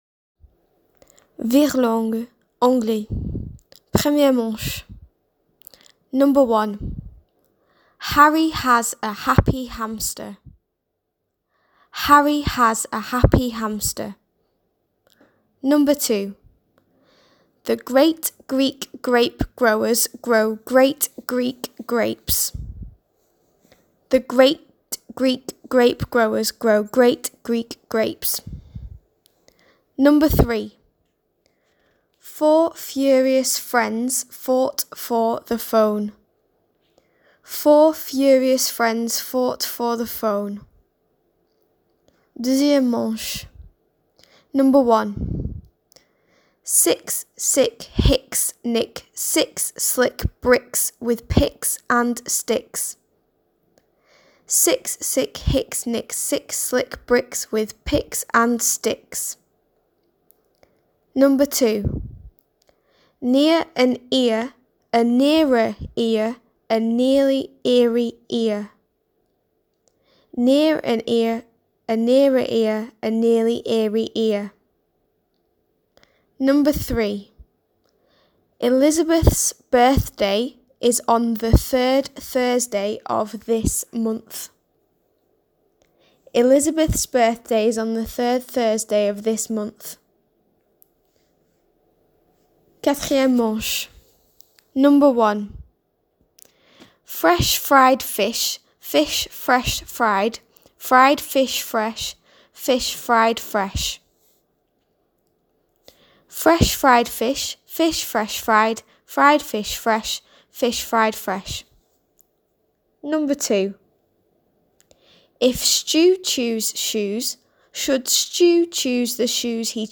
Virelangues